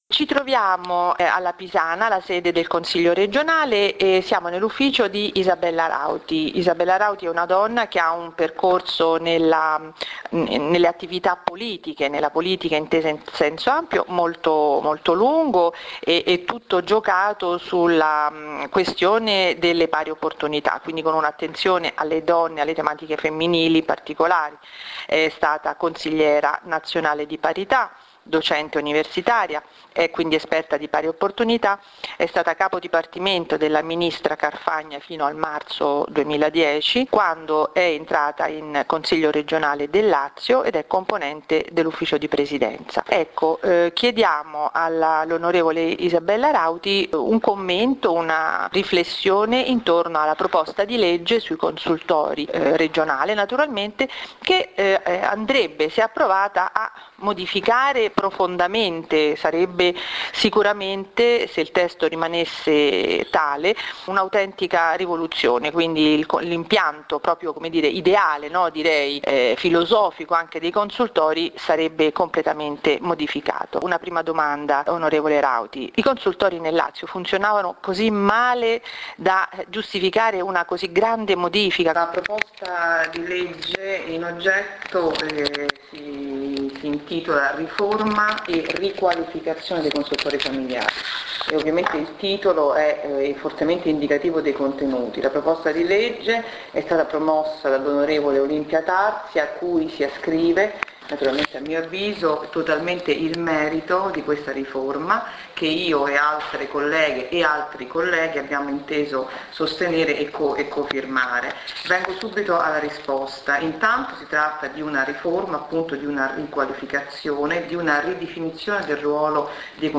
Intervista a Isabella Rauti – Consigliera Regione Lazio PdL
Intervista_ad_Isabella_Rauti_PRIMA_PARTE.mp3